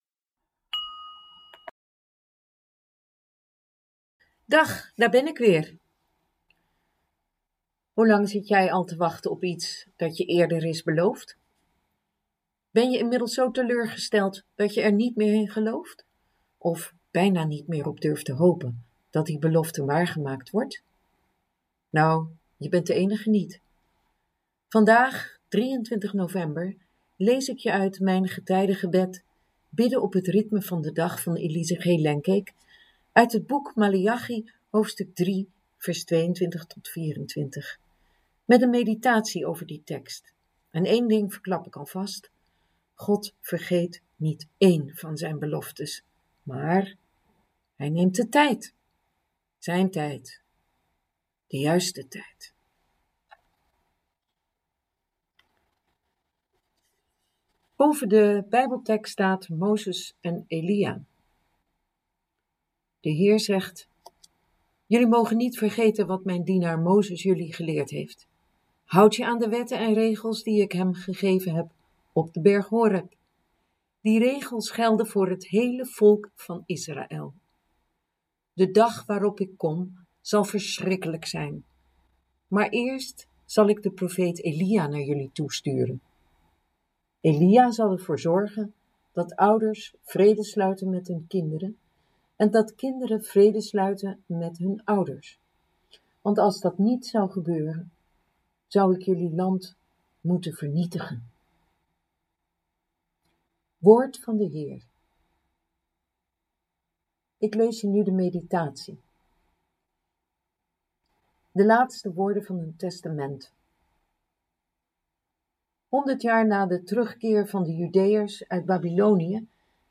Dagboek en meditatie 23 november